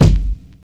Dilla Kick 2.wav